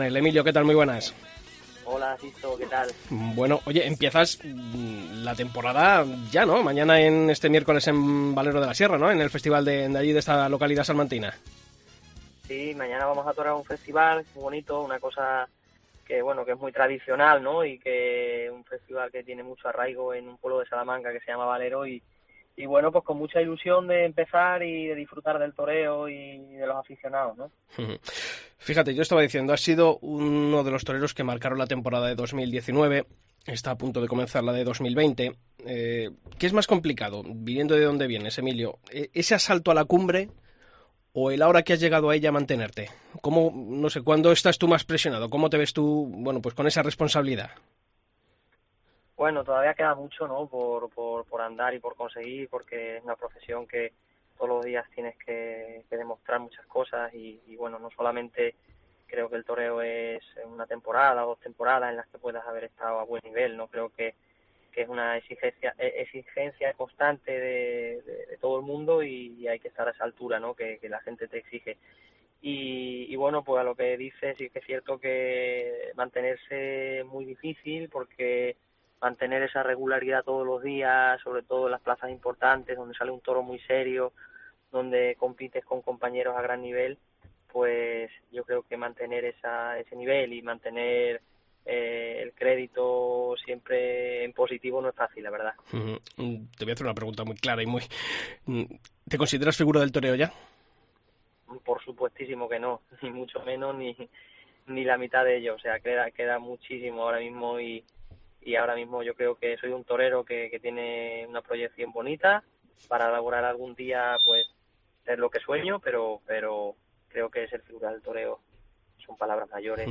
AUDIO: Hablamos con Emilio de Justo, que nos cuenta las ilusiones puestas en la campaña de 2020, su nuevo acuerdo con Simón Casas y líneas maestras de su temporad